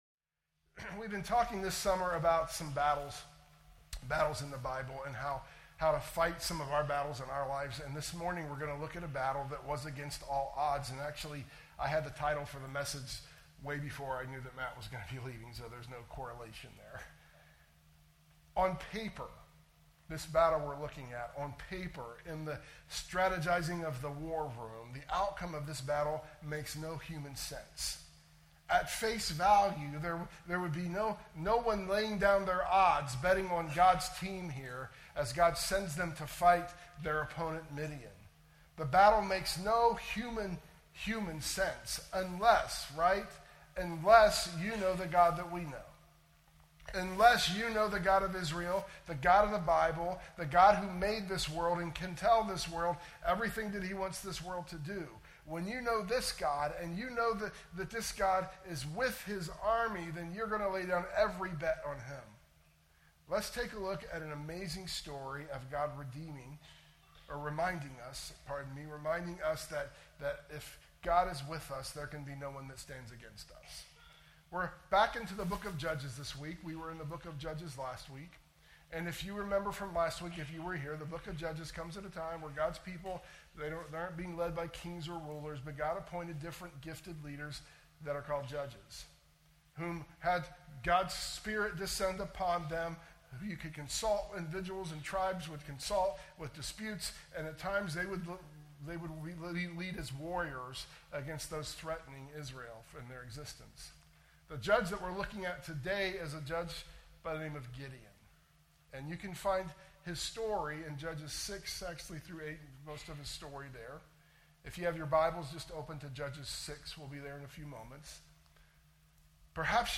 sermon_audio_mixdown_7_27_25.mp3